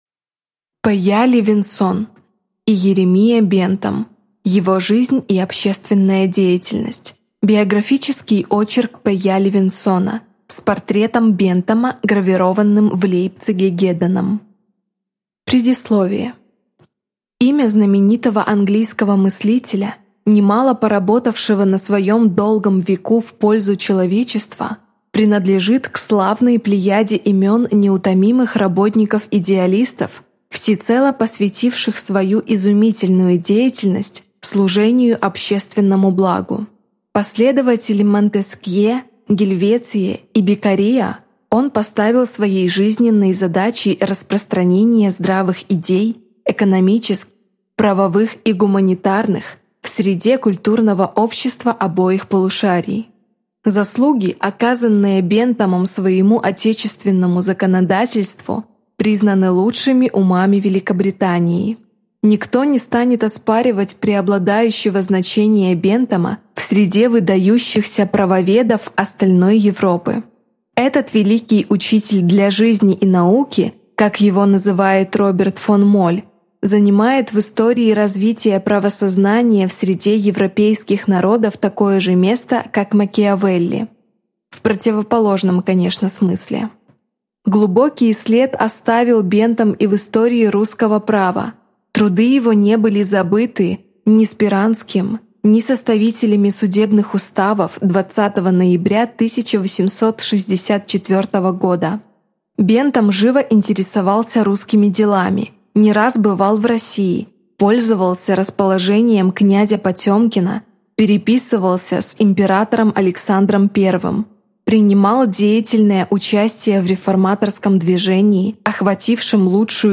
Аудиокнига Иеремия Бентам. Его жизнь и общественная деятельность | Библиотека аудиокниг